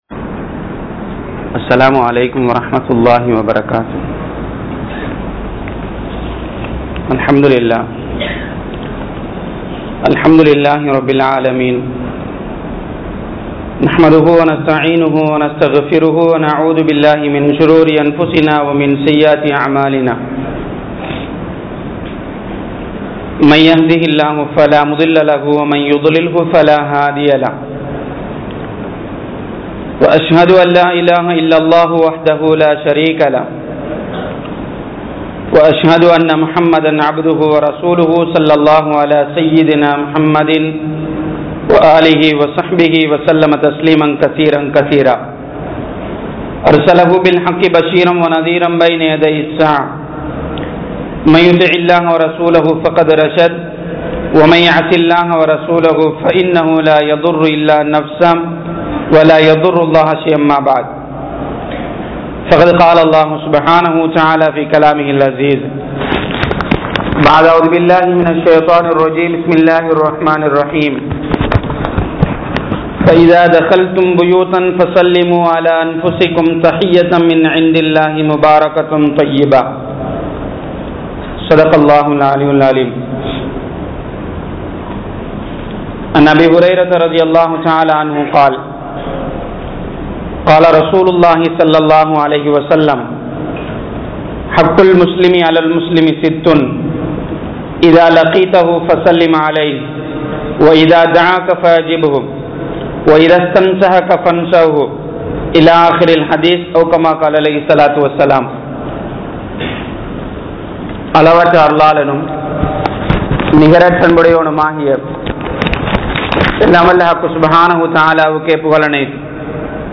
Ikram UL Muslimeen | Audio Bayans | All Ceylon Muslim Youth Community | Addalaichenai